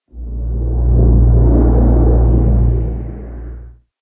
ambient / cave / cave9.ogg
cave9.ogg